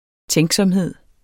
Udtale [ ˈtεŋsʌmˀˌheðˀ ]